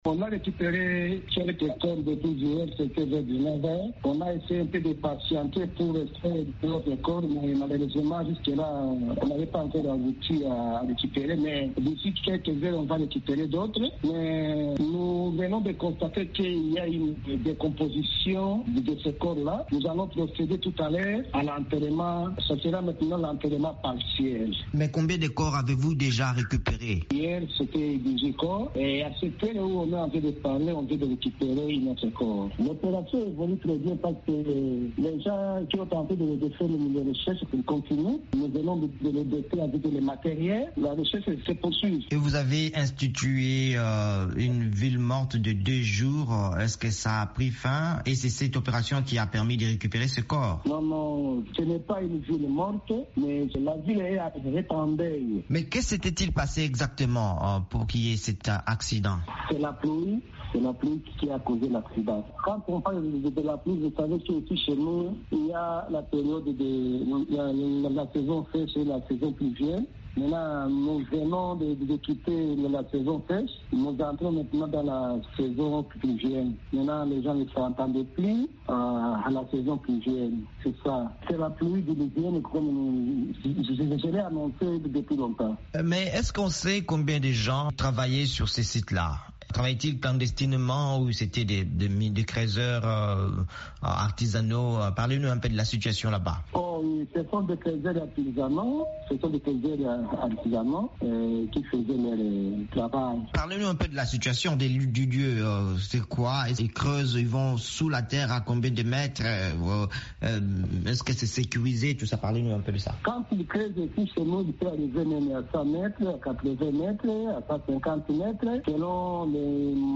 Mine d’or effondrée: entretien avec Alexandre Bundia, maire de Kamituga